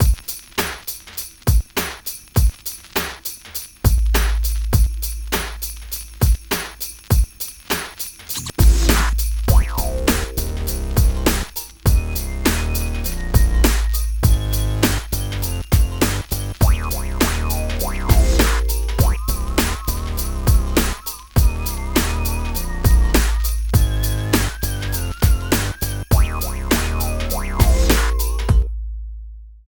13 LOOP   -R.wav